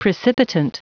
Prononciation du mot precipitant en anglais (fichier audio)
Prononciation du mot : precipitant